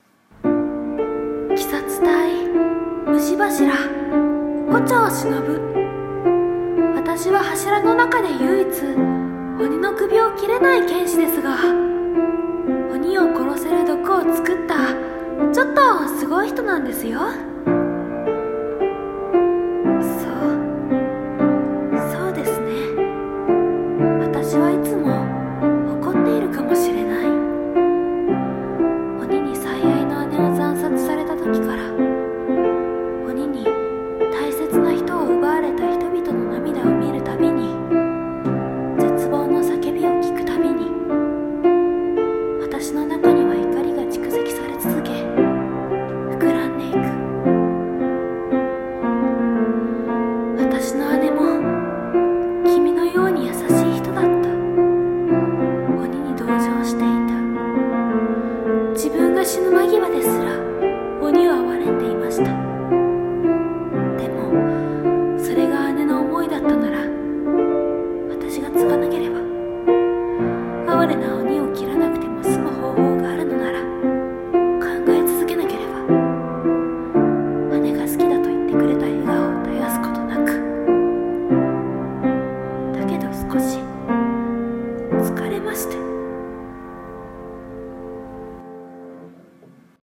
胡蝶しのぶ 声真似